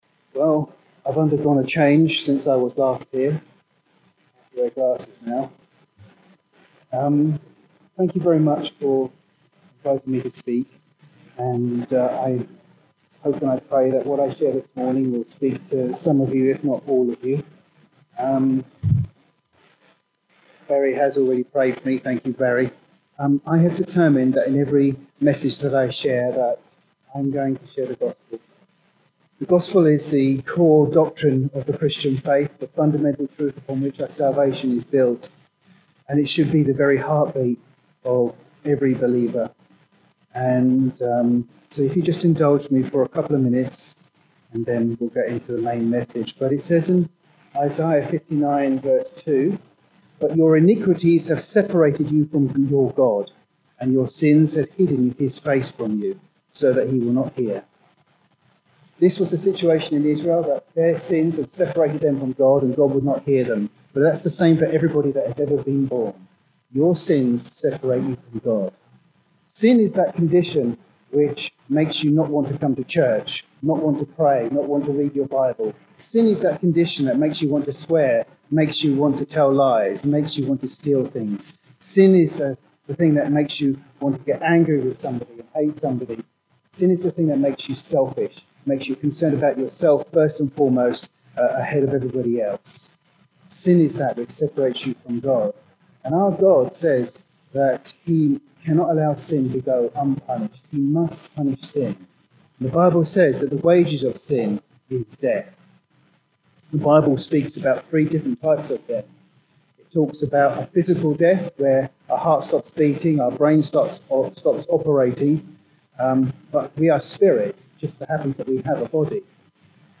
This sermon deals with how we get into a “wilderness” situation, what to do when we are in one, and how we get out of it. The “getting out of it” is not something we achieve by our efforts, but by reliance on our Father God’s grace and mercy.
{{{ No slides were used in this presentation }}} >>>>>>>>>> The audio file has been attached but as a result of a hardware problem the quality is not up to the usual standard.